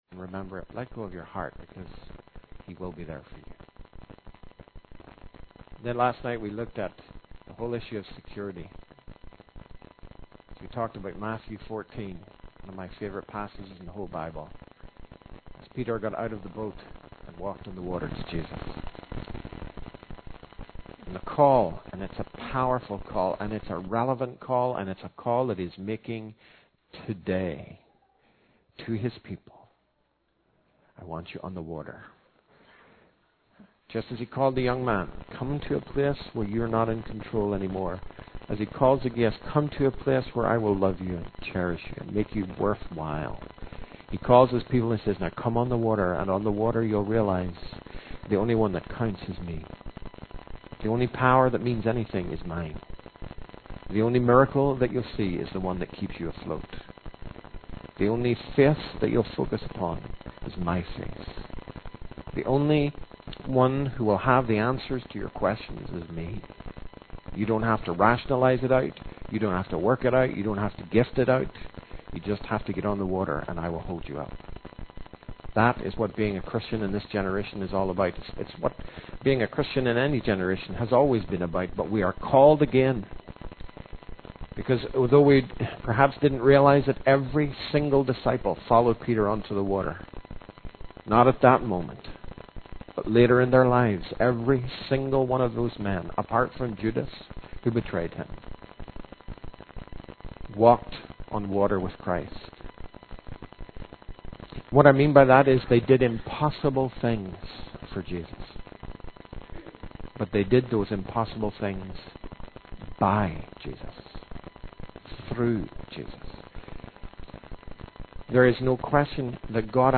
In this sermon, the speaker encourages the audience to let go of self and focus on Jesus. He emphasizes the importance of not seeking recognition or fame for oneself, but rather spreading the message of Jesus. The speaker mentions two strategies that the devil uses to shift our focus from Jesus to ourselves.